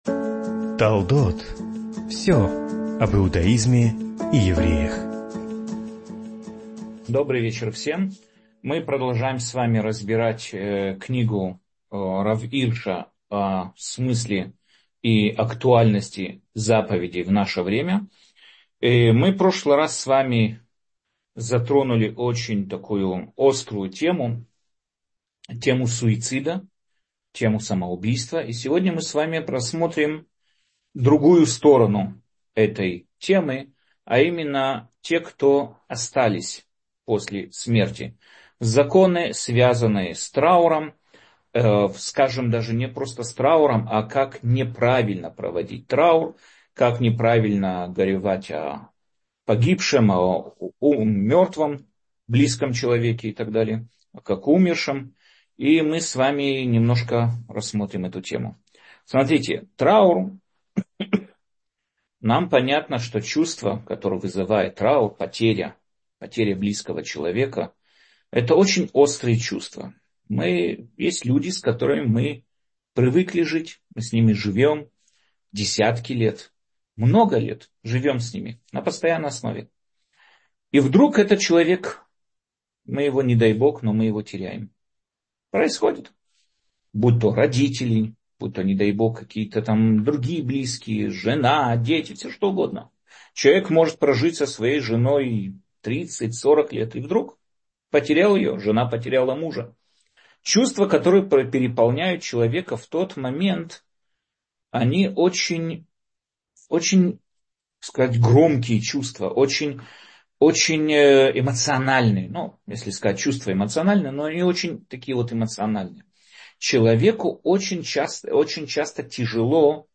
Иудаизм и евреи Аудио Аудиоуроки Как грустить согласно Торе?